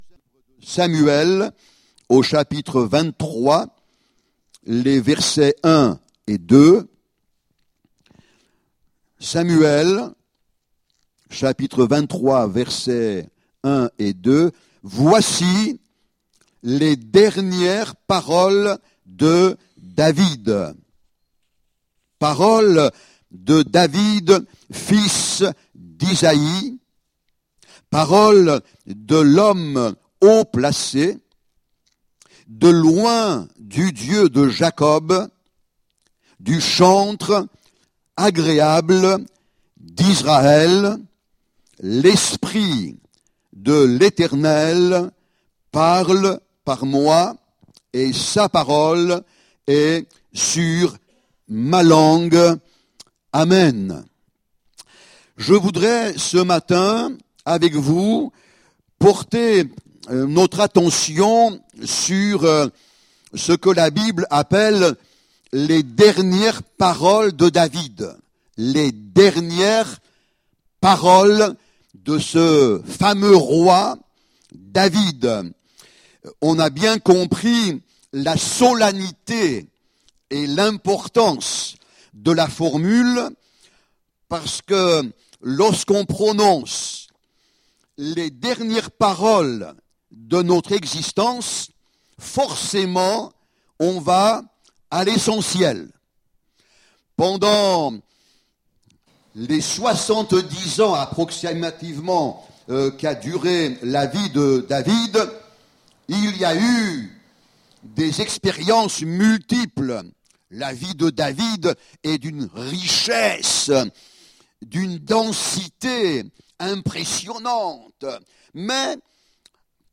15 décembre 2024 Les dernières paroles de David Prédicateur